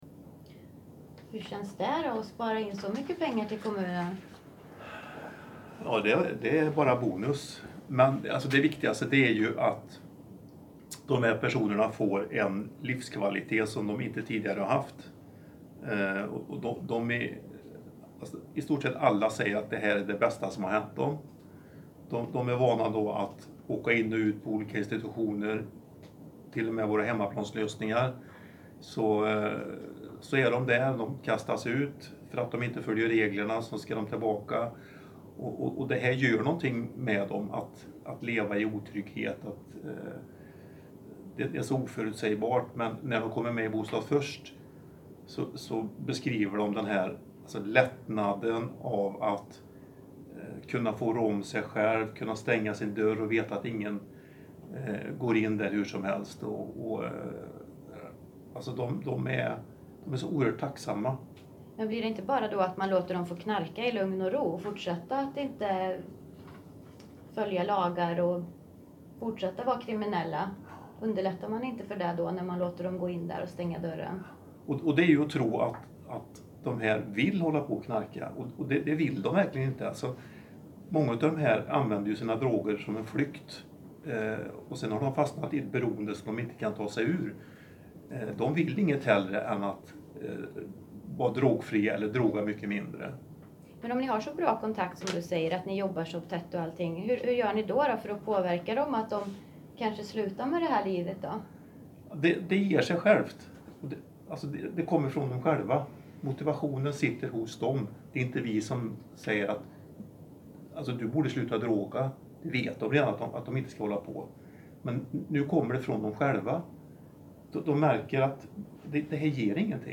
Ljudklippen nedan har vi spelat in efter presentationen vid stormötet.
Karlstad-del-4-Samtal-Bostad-först-karlstad.mp3